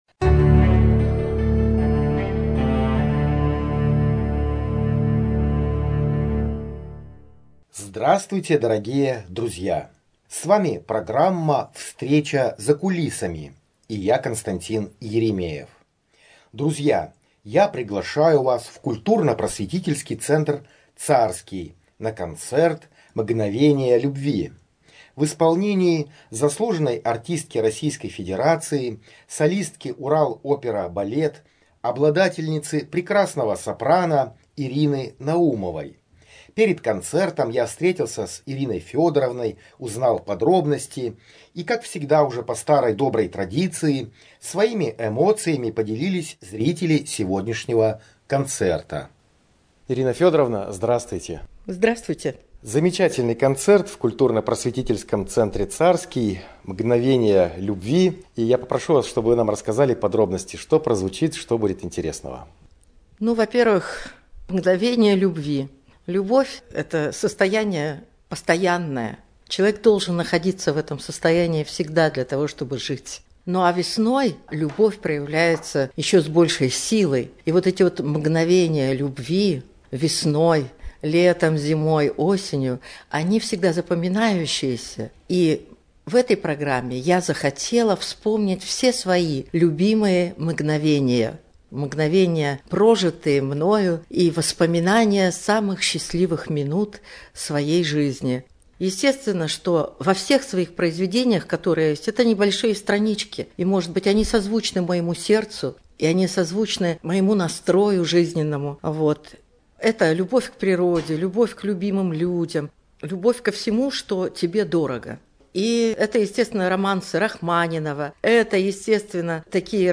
Концерт